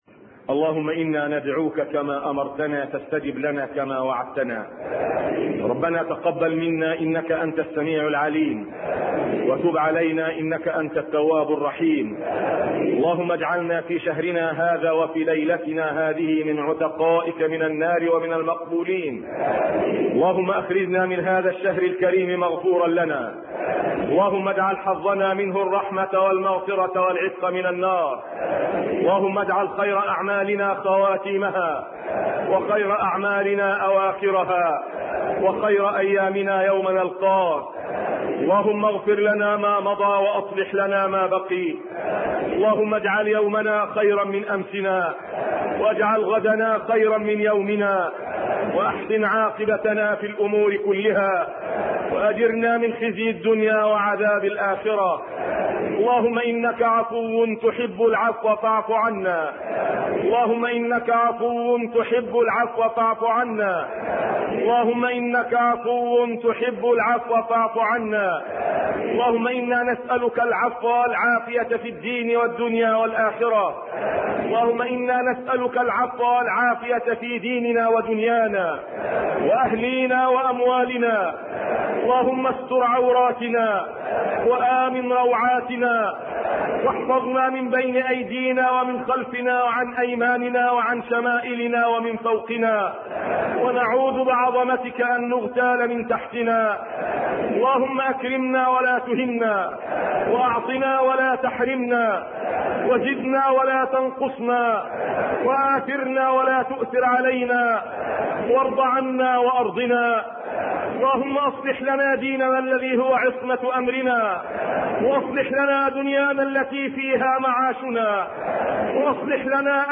دعاء القنوت بصوت الشيخ يوسف القرضاوي.
تسجيل لدعاء القنوت المؤثر بصوت الشيخ يوسف القرضاوي.